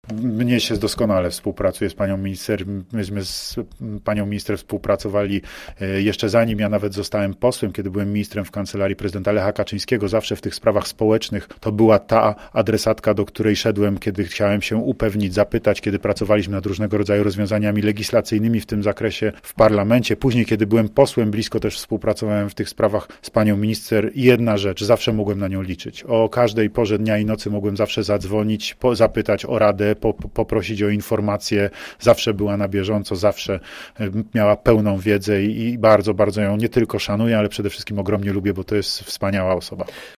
Radiu Gorzów udzielił wywiadu na wyłączność.